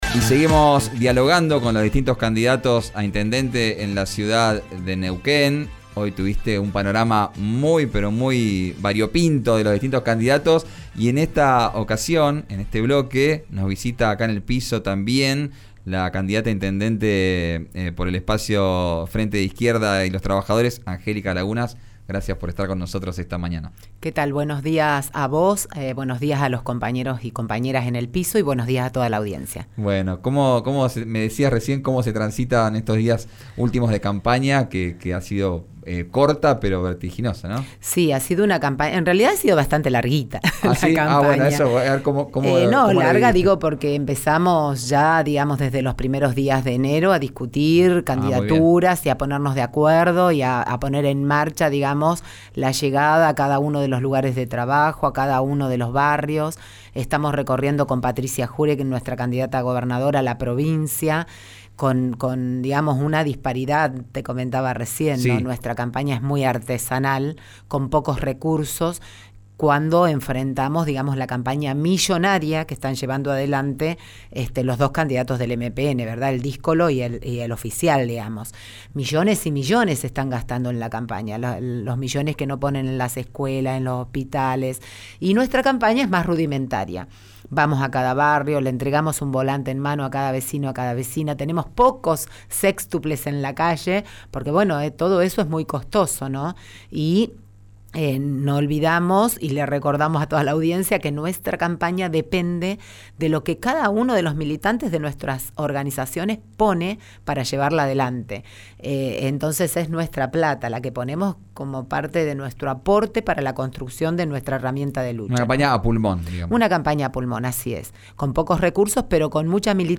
visitó el estudio de RÍO NEGRO RADIO.